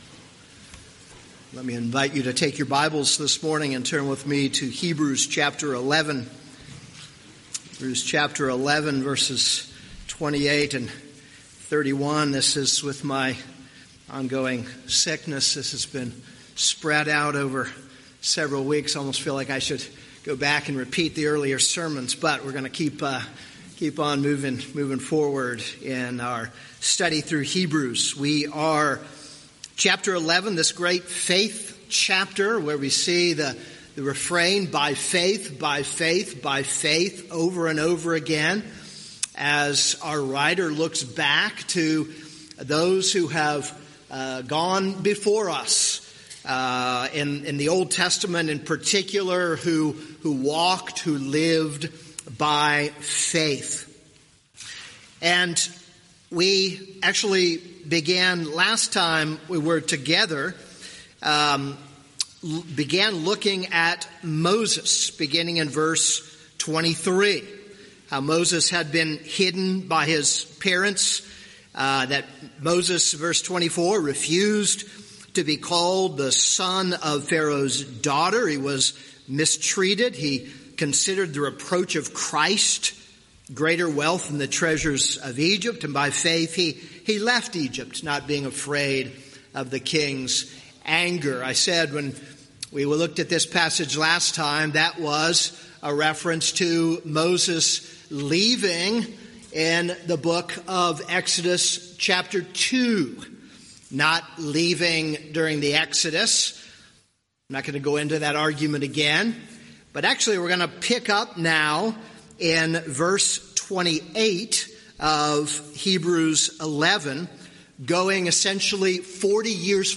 This is a sermon on Hebrews 11:28-31.